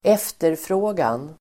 Uttal: [²'ef:terfrå:gan]